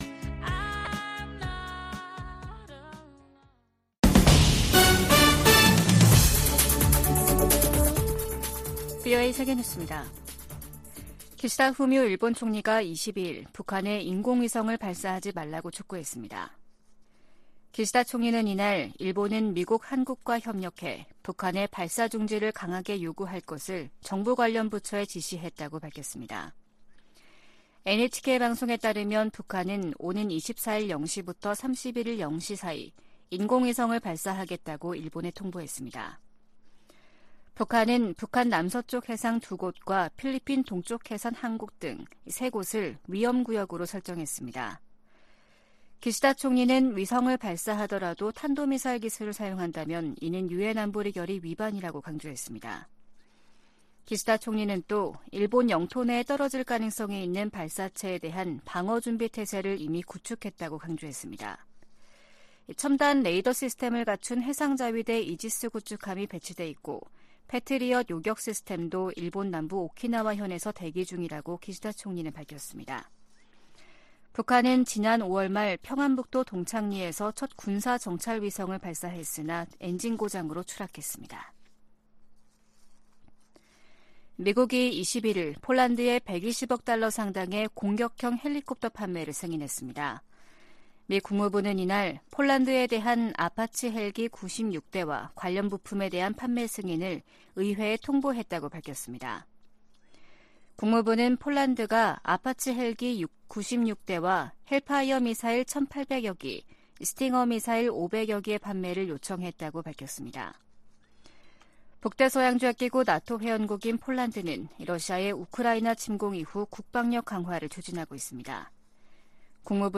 VOA 한국어 아침 뉴스 프로그램 '워싱턴 뉴스 광장' 2023년 8월 23일 방송입니다. 북한이 실패 3개월만에 군사정찰위성을 다시 발사하겠다고 예고했습니다. 백악관 국가안보회의(NSC) 인도태평양 조정관은 미한일 3국 협력이 위중해진 역내 안보를 지키기 위한 노력의 일환이라고 말했습니다. 6차례에 걸쳐 보내드리는 기획특집 [미한일 정상회의 결산] 첫 시간에 한층 격상된 3국 안보 협력 부분을 살펴봅니다.